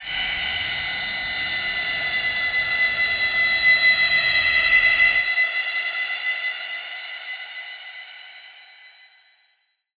G_Crystal-B8-mf.wav